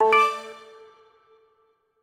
Index of /phonetones/unzipped/BlackBerry/Priv/notifications